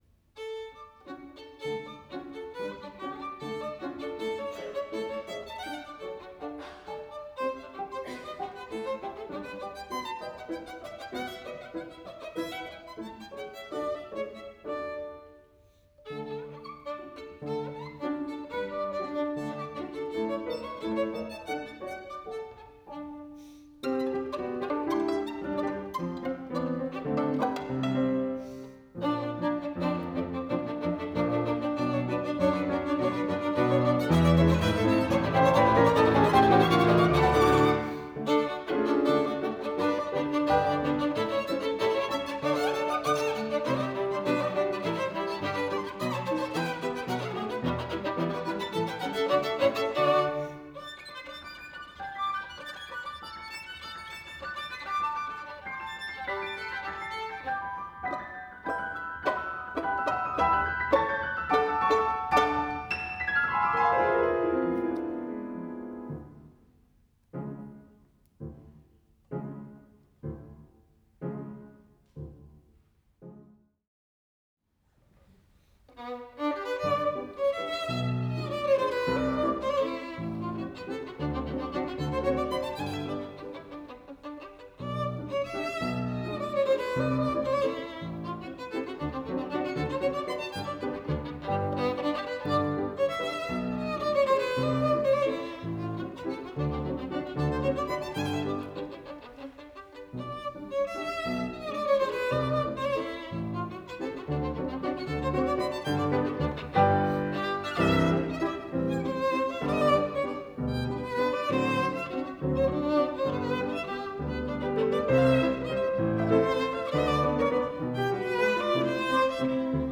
錄音地點：高雄衛武營演奏廳